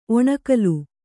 ♪ oṇakalu